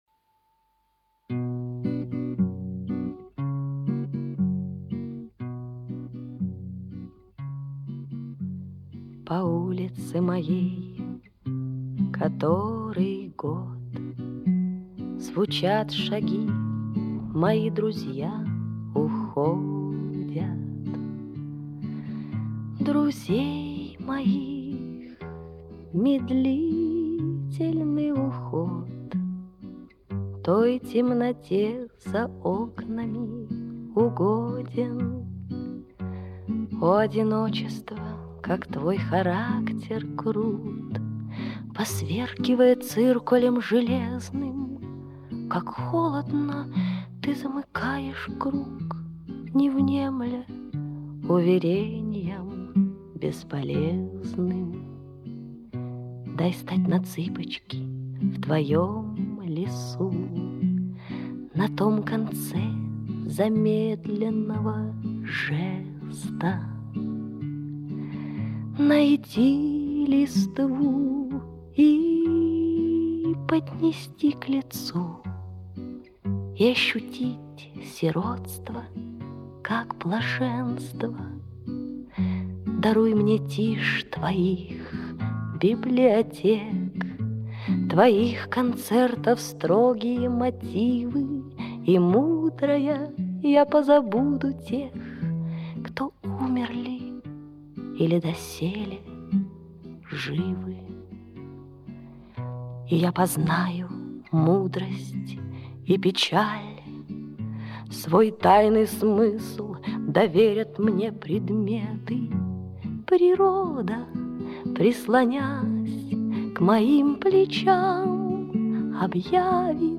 грустная песня... очень...